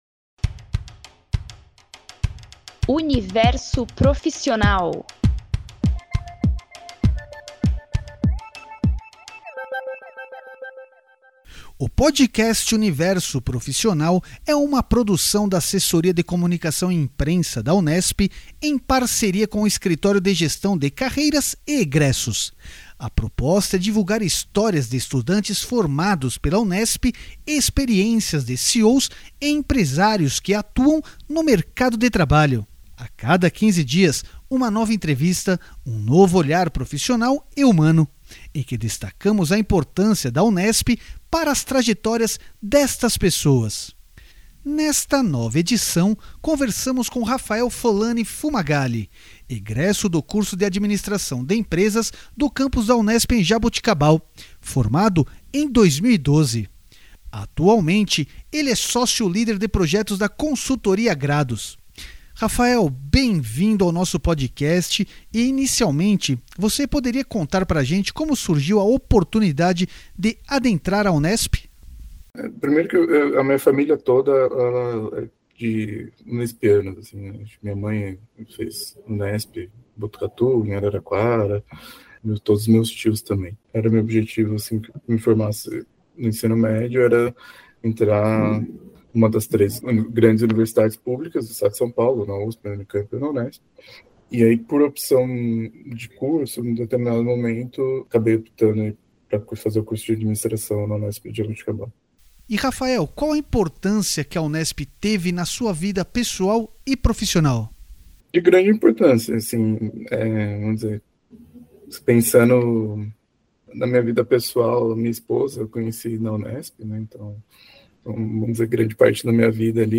A proposta é divulgar histórias de estudantes formados pela Unesp e experiências de CEOs e empresários que atuam no mercado de trabalho. A cada quinze dias, uma nova entrevista e um novo olhar profissional e humano, em que destacamos a importância da Unesp para as trajetórias destas pessoas.